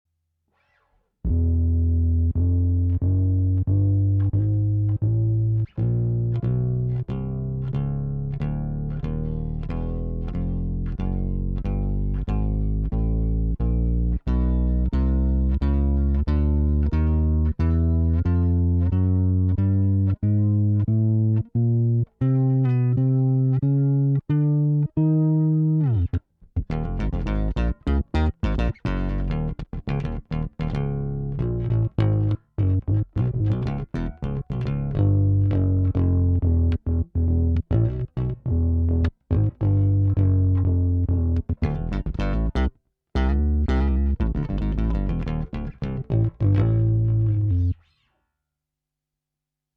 Patch 3 has PWM applied to the octave up signal just for fun.
QM+Felix+Octaver+2.mp3